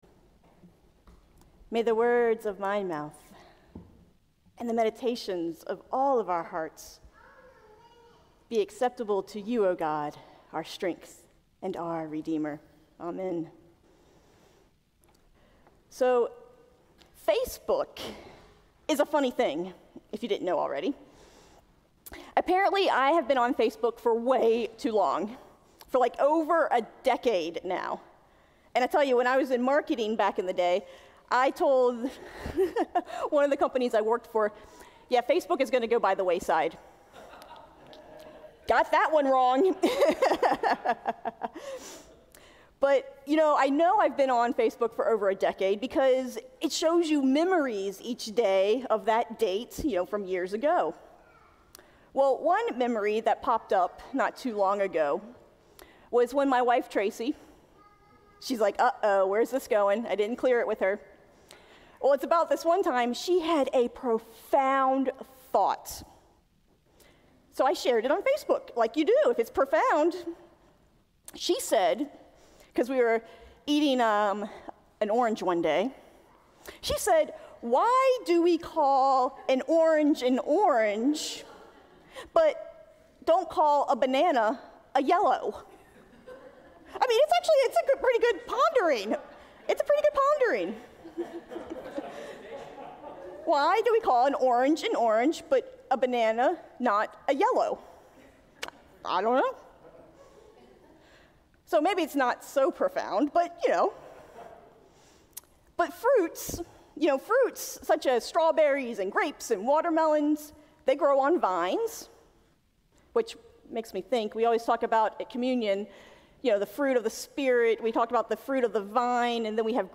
Sermon on the Fruits of the Spirit from Galatians 5.